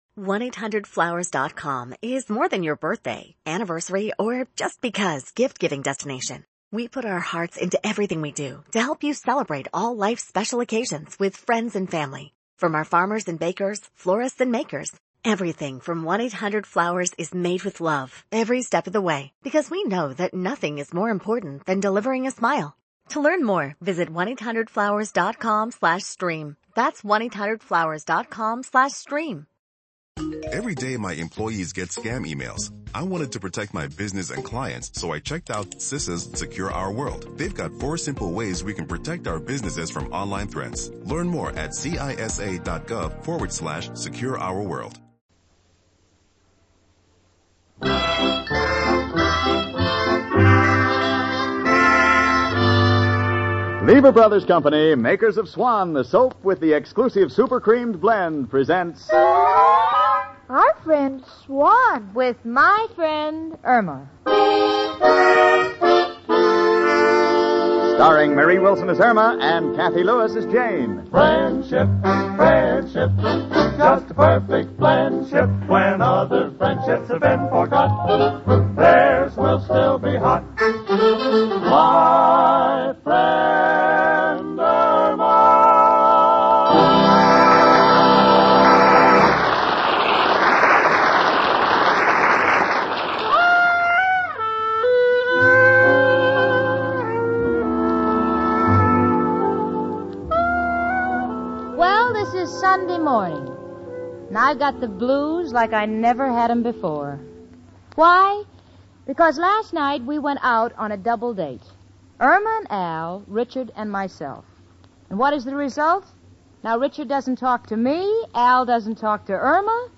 "My Friend Irma," the classic radio sitcom that had audiences cackling from 1946 to 1952!
Irma, played to perfection by the inimitable Marie Wilson, was the quintessential "dumb blonde."